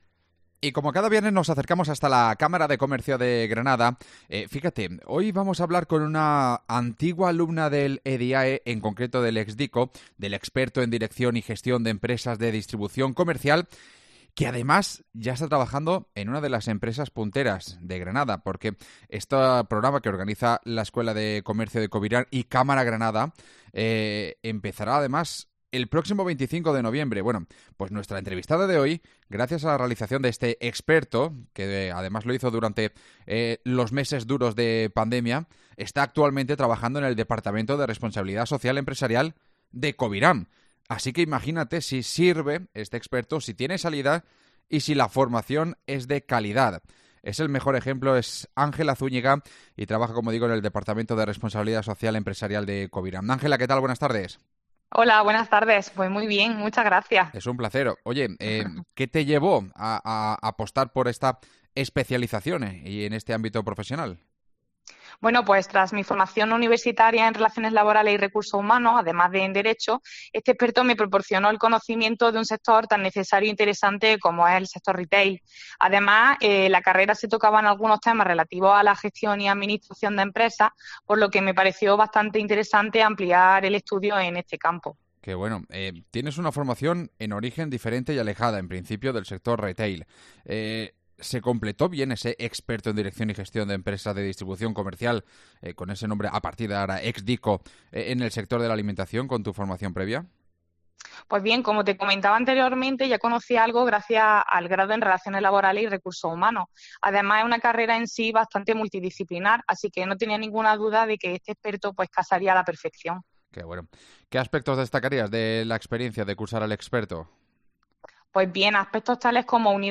Hablamos con una exalumna del experto que ahora trabaja para la cooperativa granadina